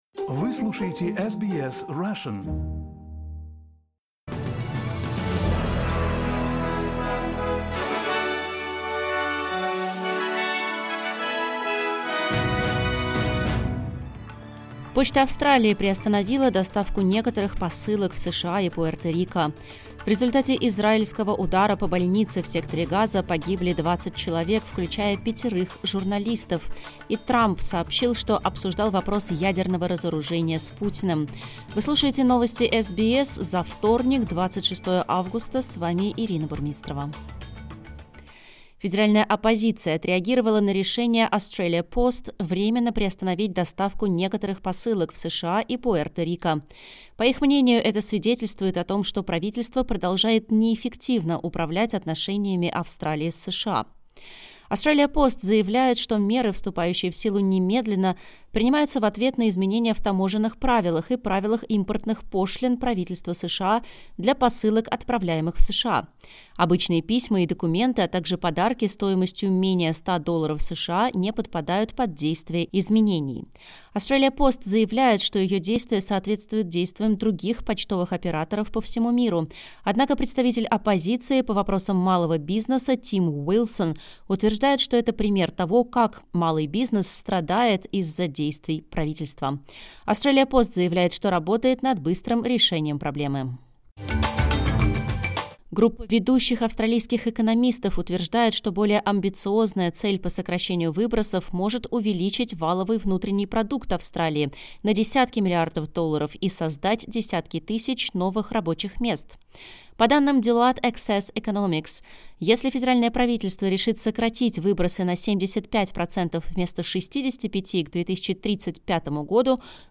Новости SBS на русском языке — 26.08.2025